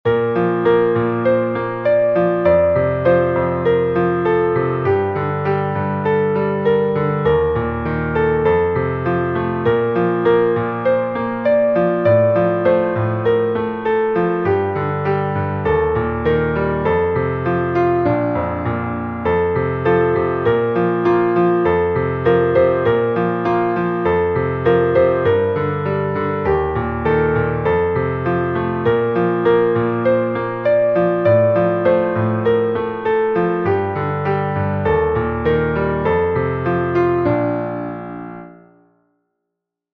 Tonalità: re maggiore
Metro: 6/8
per clarinetto